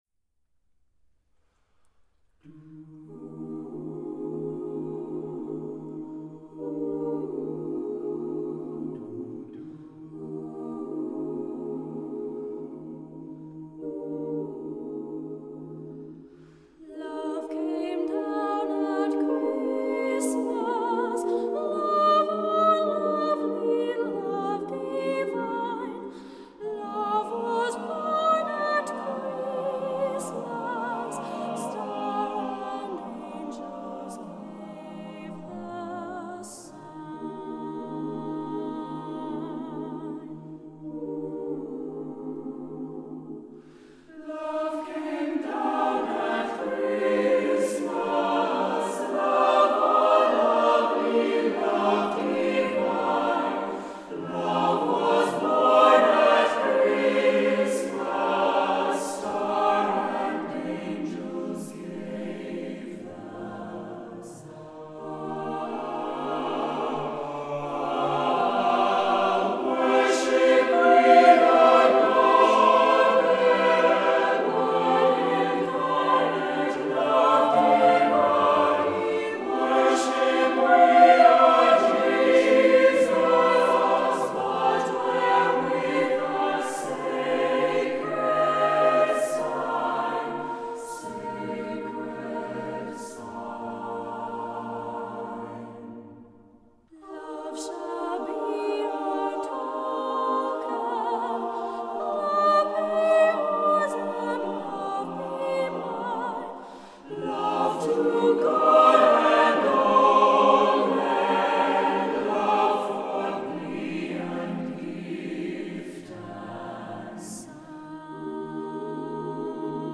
for choir